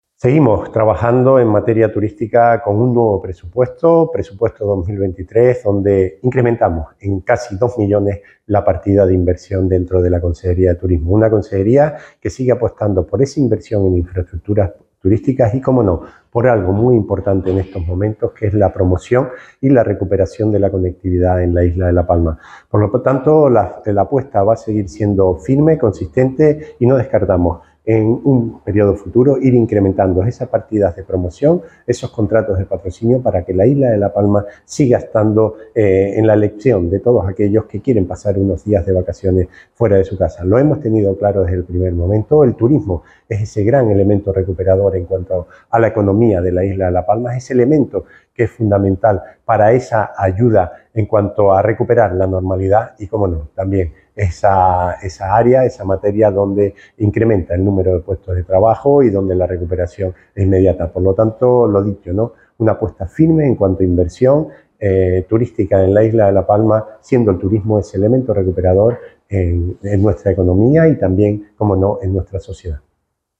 El consejero de Turismo del Cabildo de La Palma, Raúl Camacho, ha confirmado que este año su área alcanzará los 9,8 millones de euros, lo que supone casi 2 millones de euros más que en el presupuesto del ejercicio anterior.
Declaraciones audio Raúl Camacho presupuesto.mp3